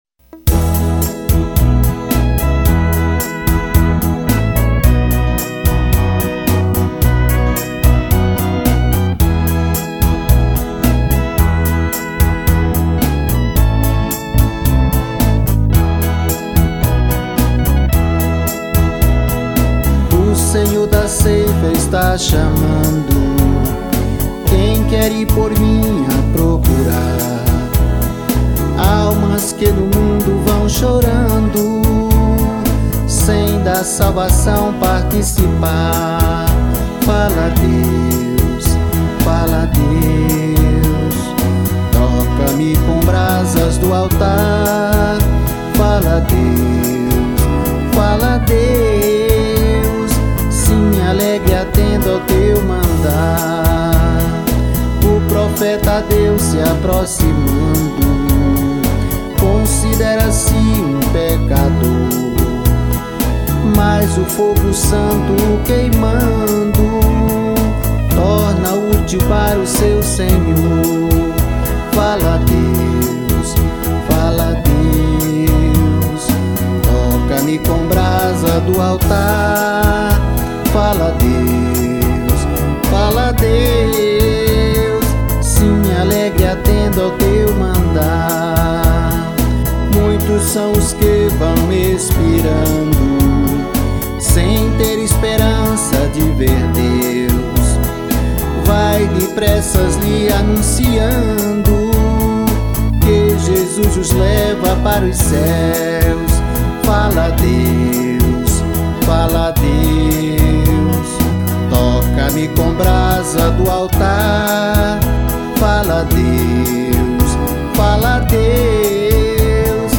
EstiloGospel